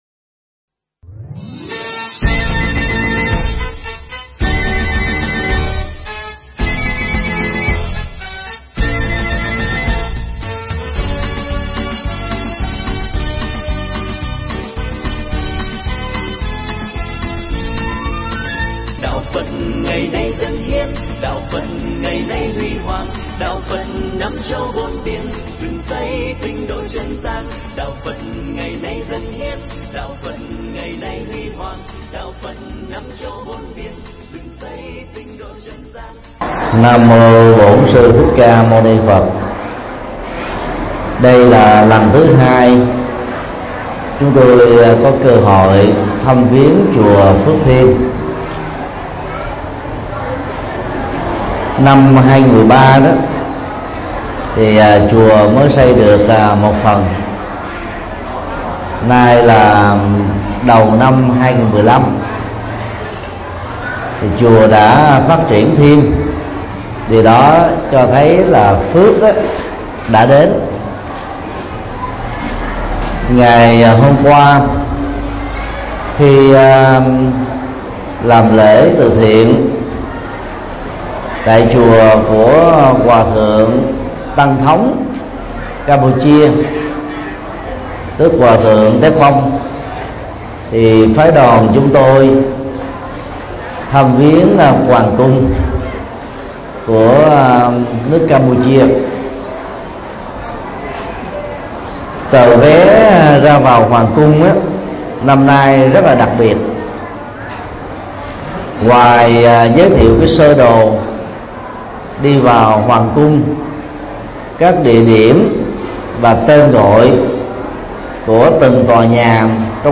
Mp3 Thuyết Pháp Sức khỏe
Giảng tại chùa Phước Thiên, Campuchia